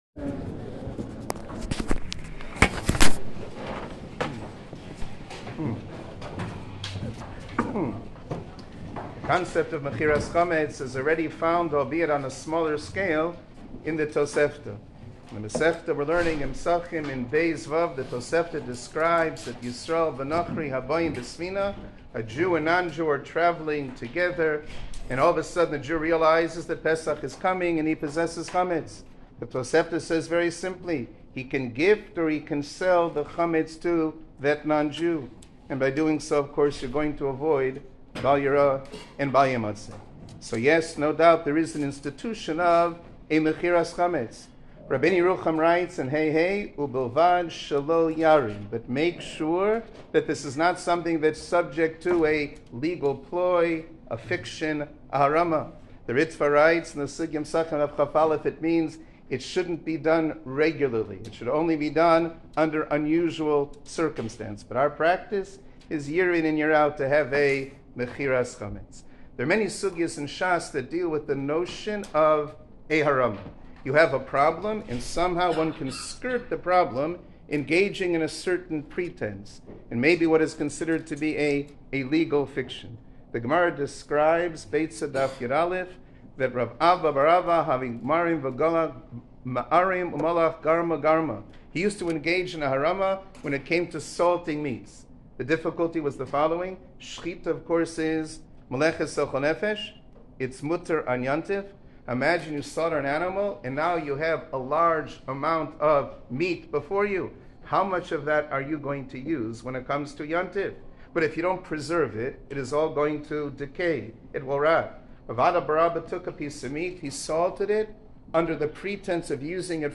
מכירת חמץ sicha